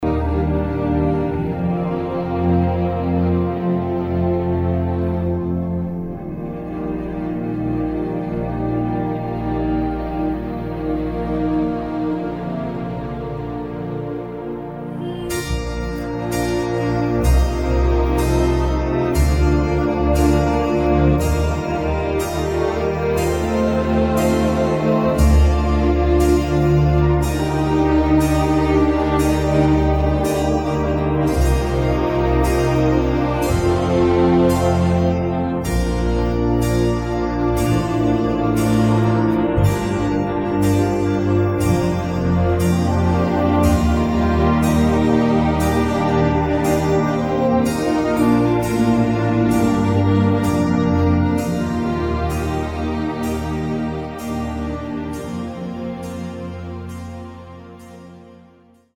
음정 원키